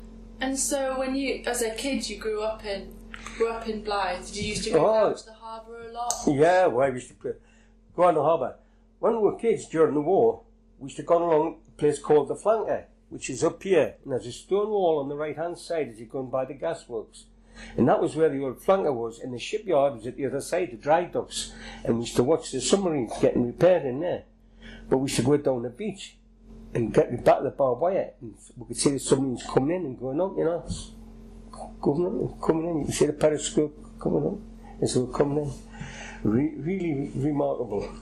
These sound files are extracts (short, edited pieces) from longer oral history interviews preserved by Northumberland Archives.